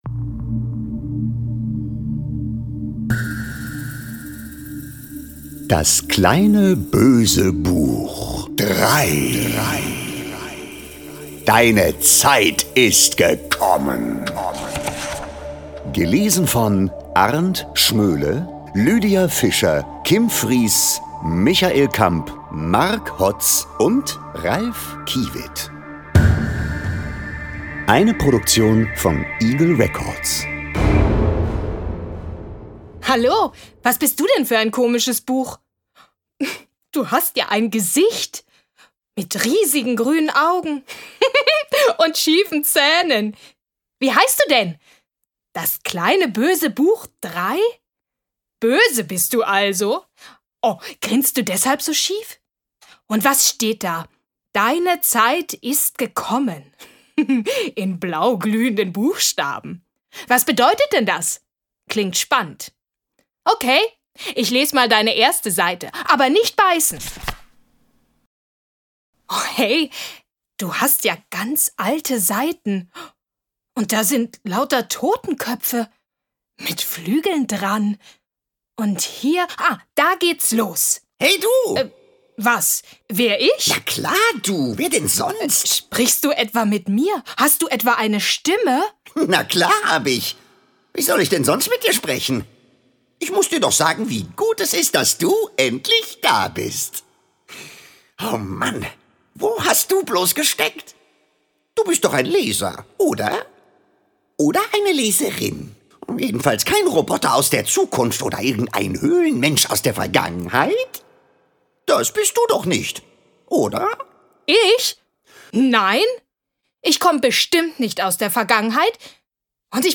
Hörbuch: Das kleine Böse Buch 3.